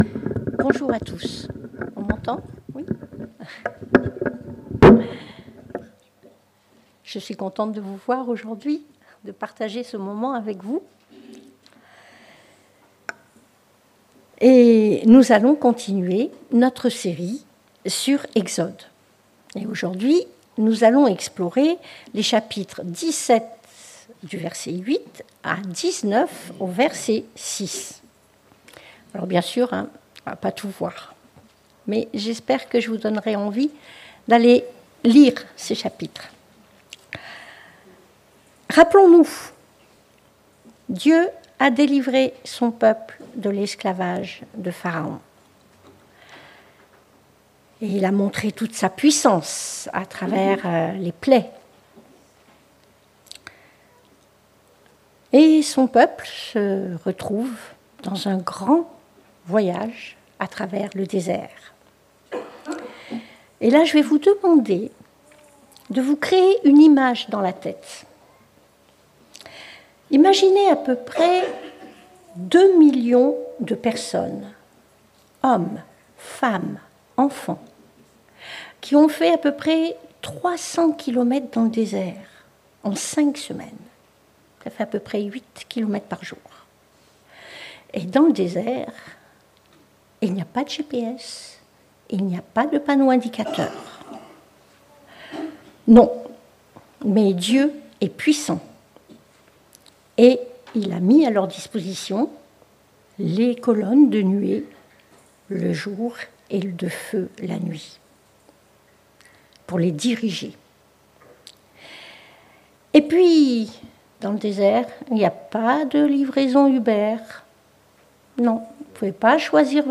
Le plan de Dieu pour les nations - Prédication de l'Eglise Protestante Evangélique de Crest sur le livre de l'Exode
Exode Prédication textuelle